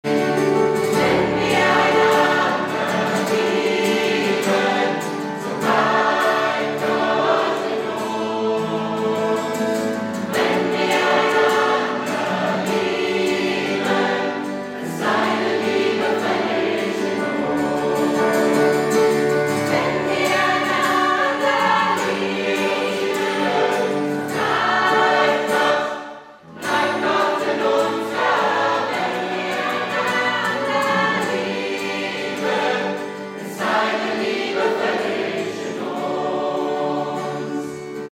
Notation: SATB
Tonart: D, C
Taktart: 6/8
Tempo: 78 bpm
Parts: 2 Verse, Refrain, Bridge